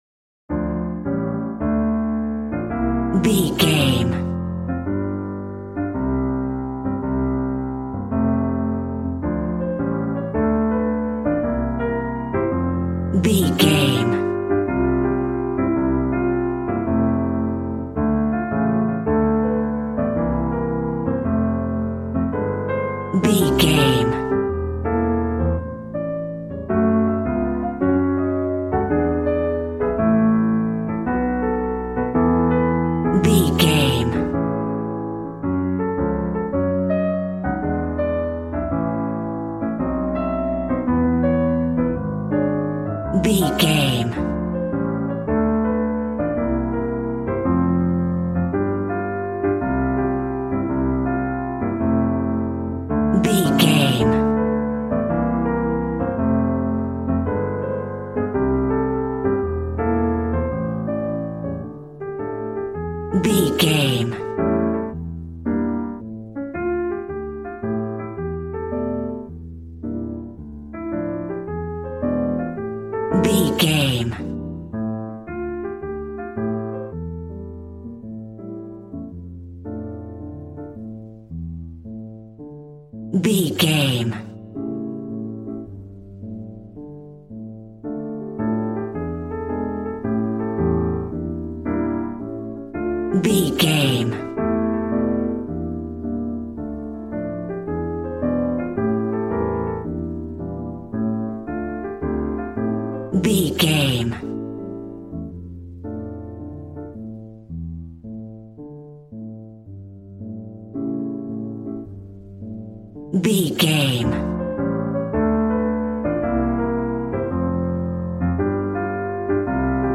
Smooth jazz piano mixed with jazz bass and cool jazz drums.,
Aeolian/Minor
smooth
drums